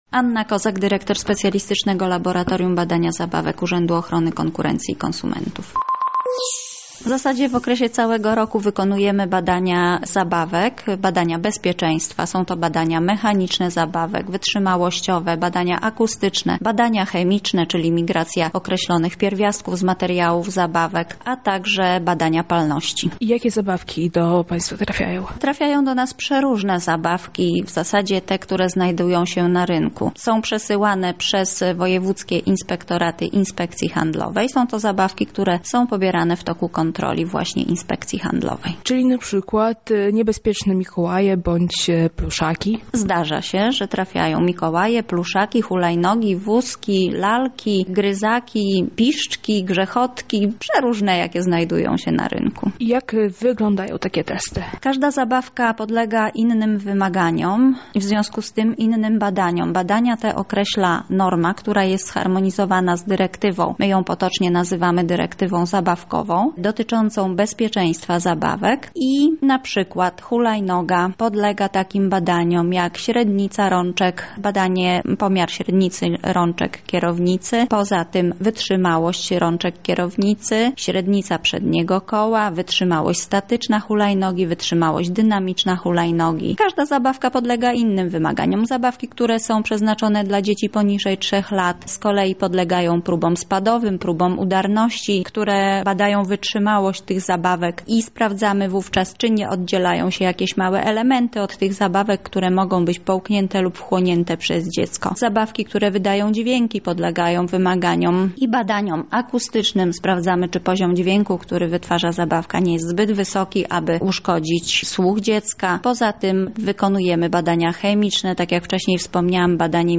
O testowaniu zabawek opowie ekspert